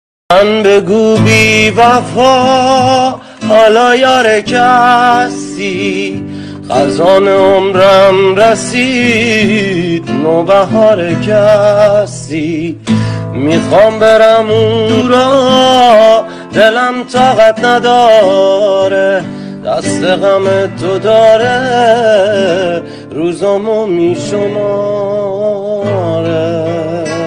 صدای مرد با گیتار